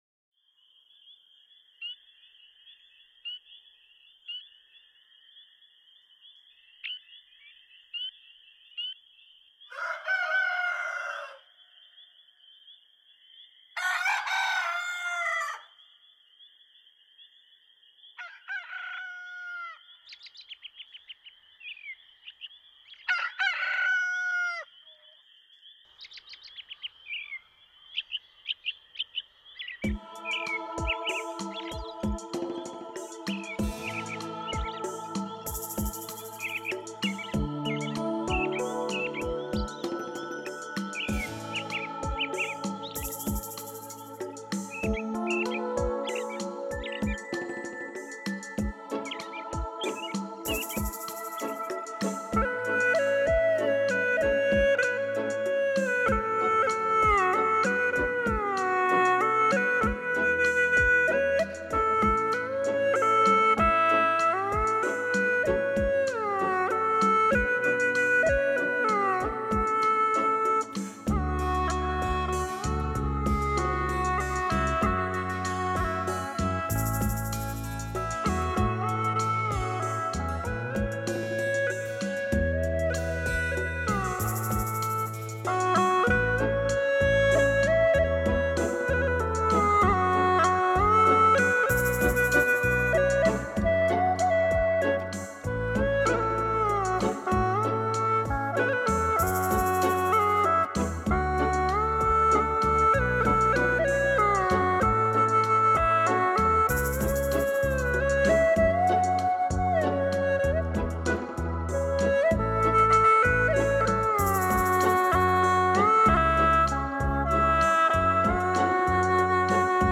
音效完美合理地分布在各个声道，您能体会极致的音乐细节，
感受准确的声音移动效果，人犹如被天籁所编织的音幕包围，